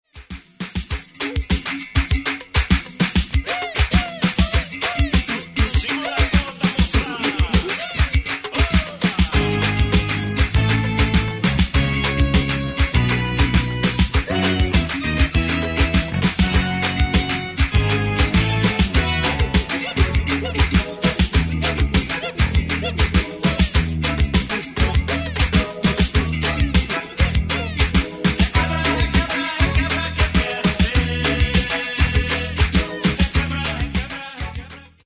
Genere:   Afro Brasil
12''Mix Extended